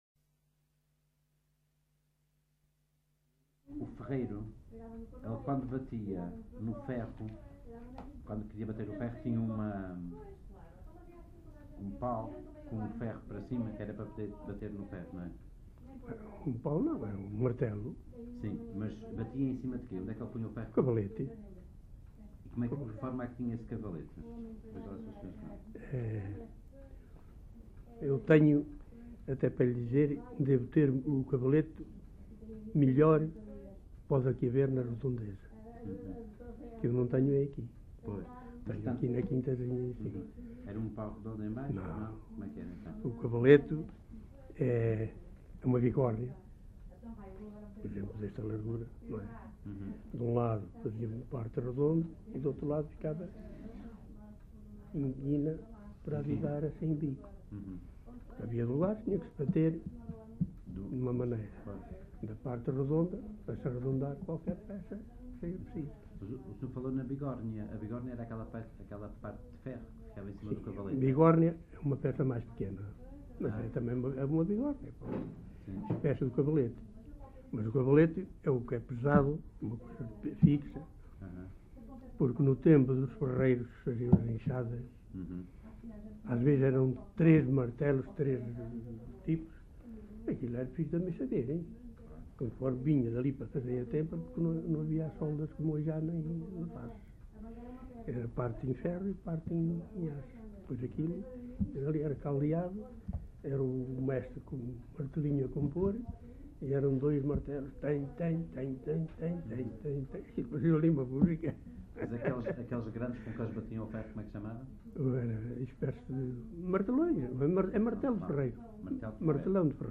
LocalidadeFigueiró da Serra (Celorico da Beira, Guarda)